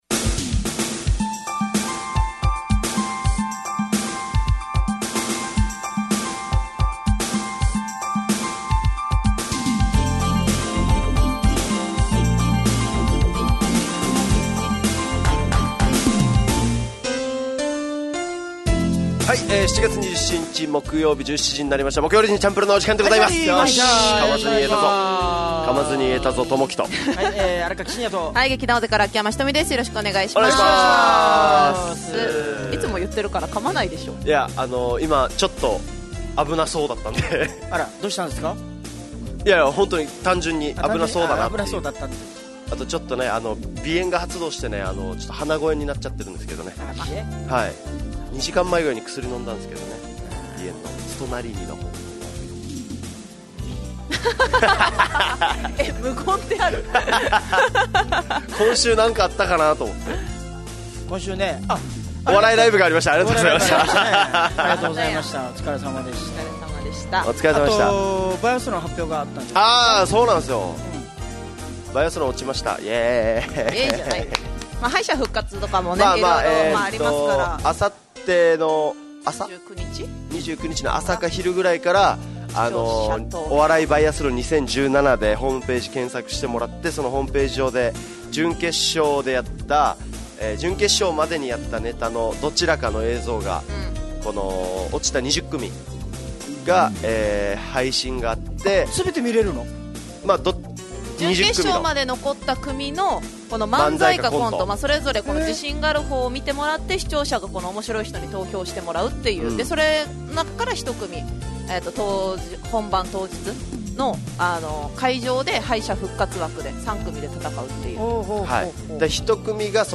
fm那覇がお届けする沖縄のお笑い集団オリジンと劇団O.Z.Eメンバー出演のバラエティ番組